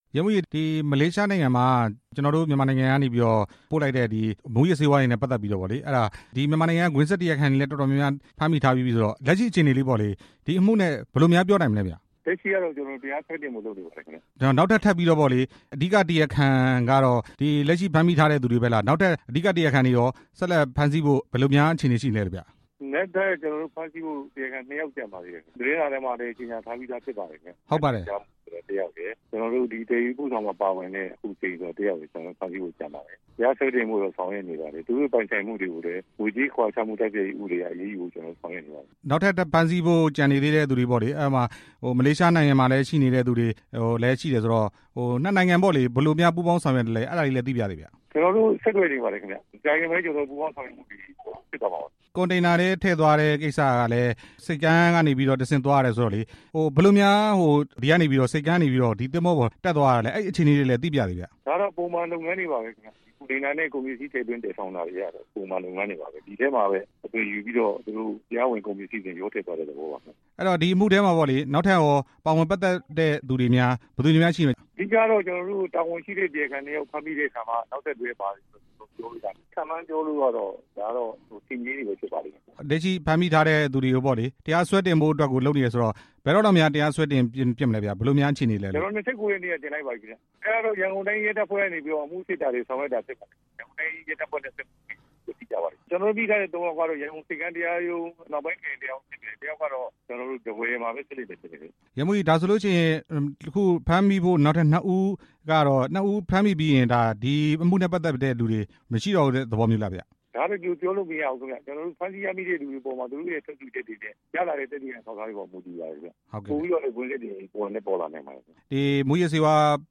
မလေးမူးယစ်မှု ၄ ဦးကို တရားစွဲဆိုတဲ့အကြောင်း မေးမြန်းချက်